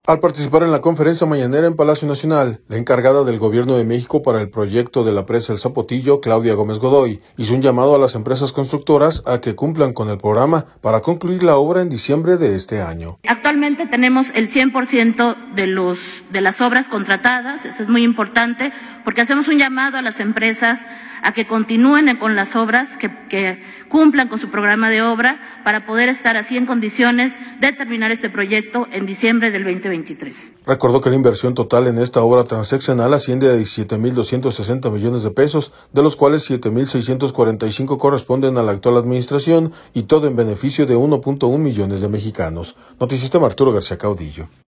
Al participar en la conferencia Mañanera en Palacio Nacional, la encargada del Gobierno de México para el proyecto de la presa El Zapotillo, Claudia Gómez Godoy, hizo un llamado a las empresas constructoras, a que cumplan con el programa, para concluir la obra en diciembre de este año.